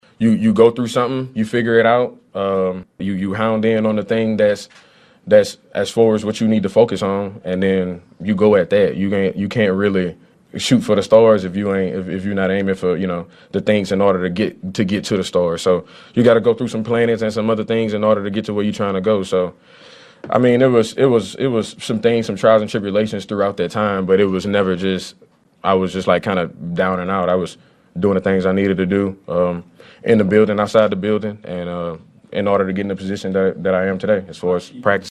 Running back Clyde Edwards-Helaire also met with the media. He struggled through a season of inefficiency and injuries during his third year.